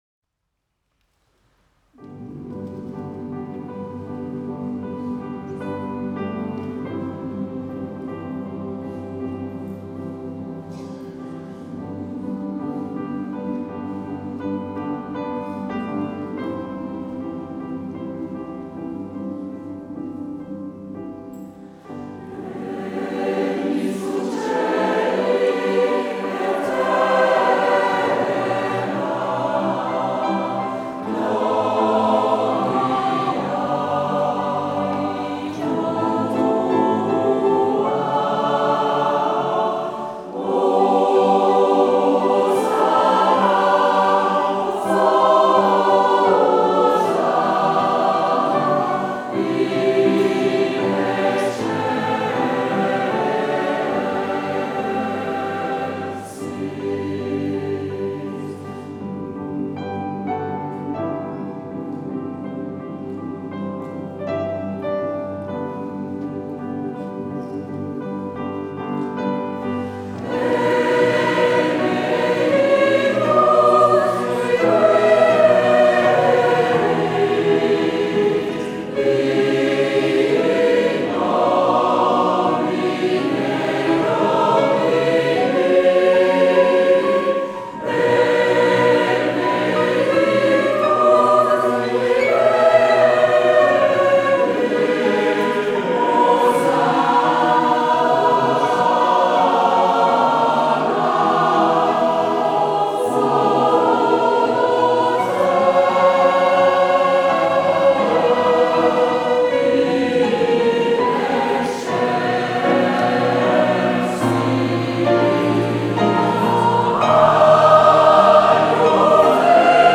Pièces extraites de concerts